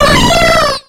Cri de Cotovol dans Pokémon X et Y.